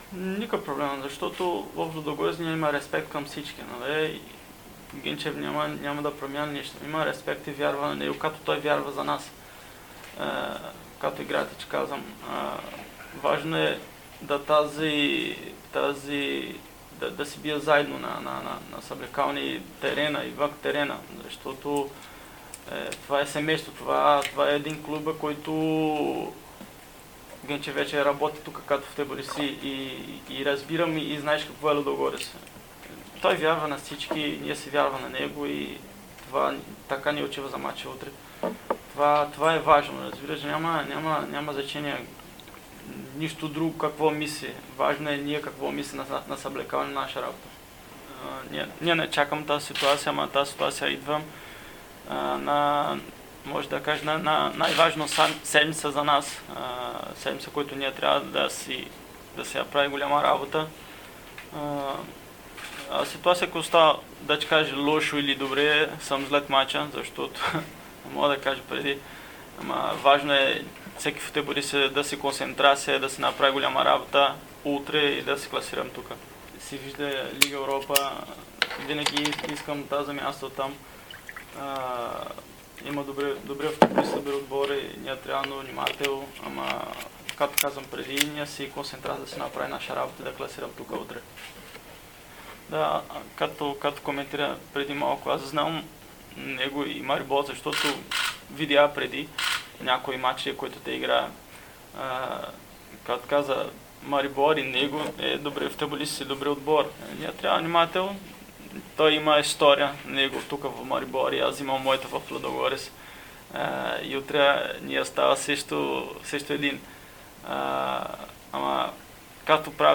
Лудогорец излиза срещу Марибор в Словения в четвъртък (29 юли) с начален час 21:15. Един от лидерите на разградчани - Марселиньо, сподели своите очаквания на офицалния брифинг преди двубоя и как временният треньор Станислав Генчев се вписва в тази роля: